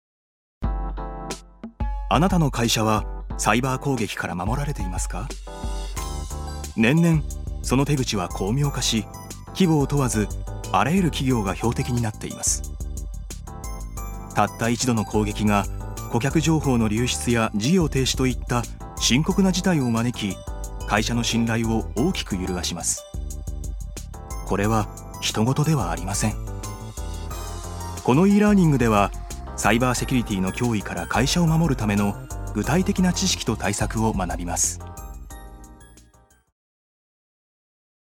所属：男性タレント
ナレーション３